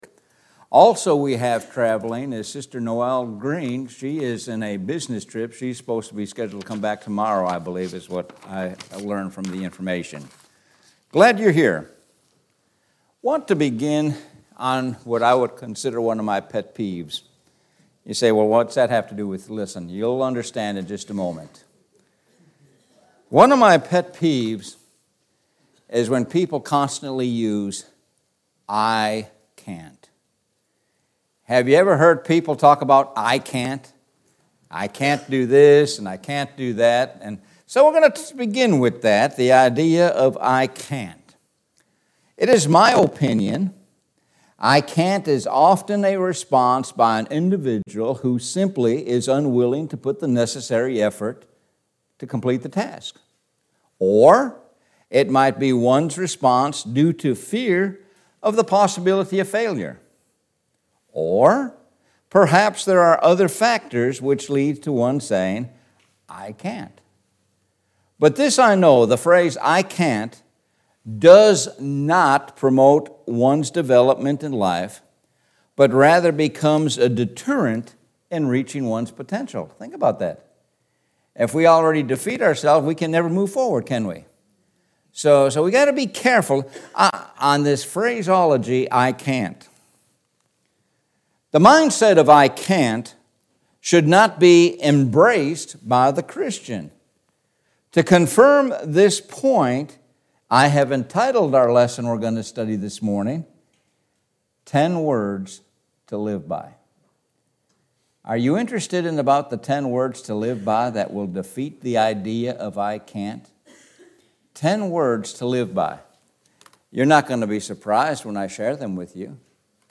Sun AM Sermon – I cant